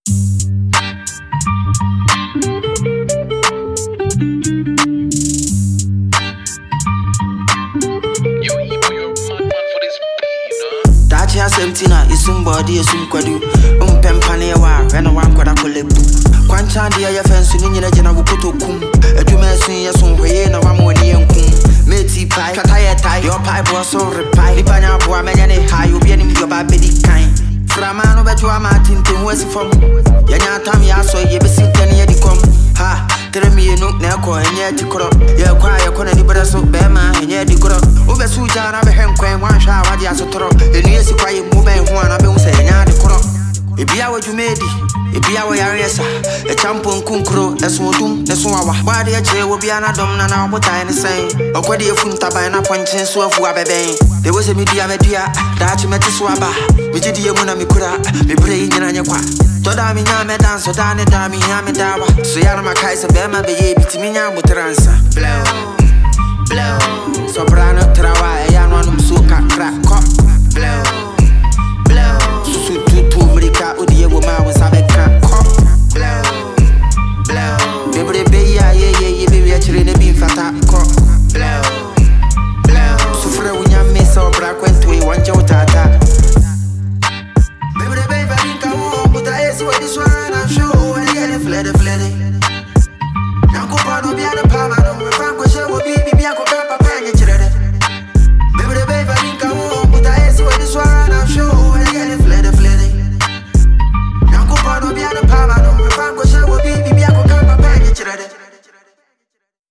a hard-hitting anthem
Backed by a gritty beat and sharp delivery